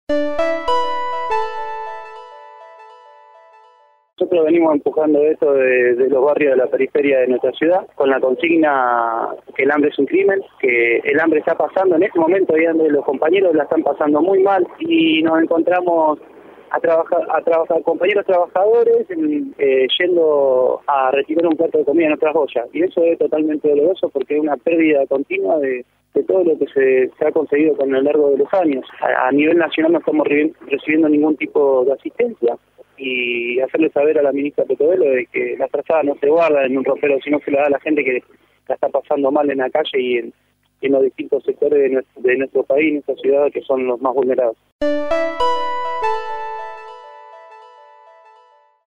En contacto con el móvil de LT3